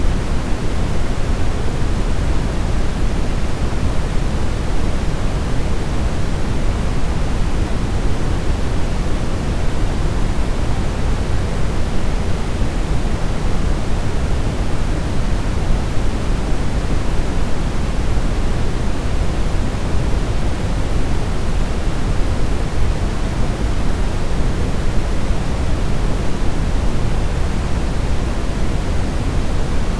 La puissance sonore décroît de 6 dB par octave lorsque la fréquence augmente (densité proportionnelle à 1/f²).
Bruit Brownien
bruitbrun.mp3